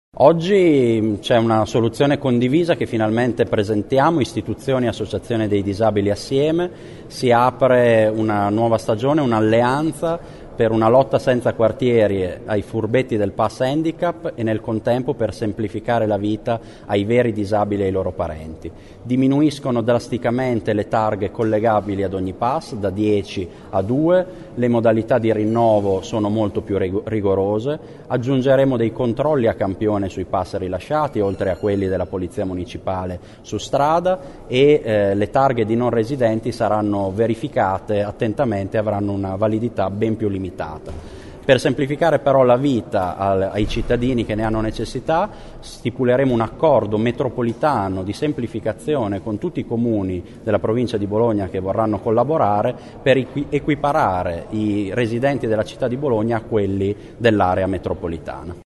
Ascolta l’assessore Colombo